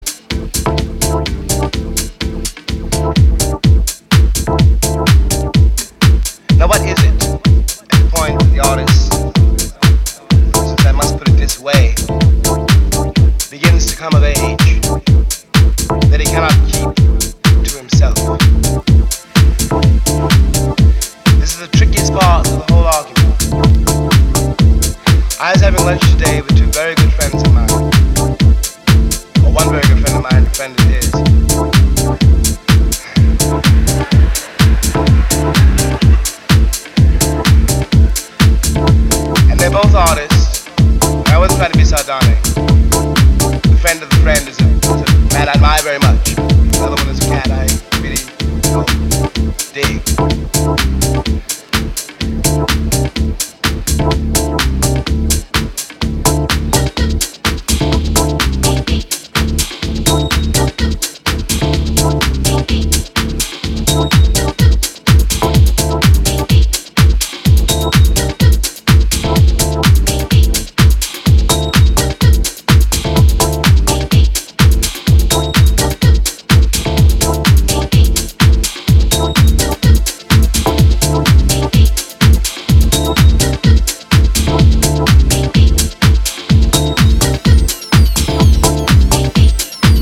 同調するメロディーとベースラインで先導する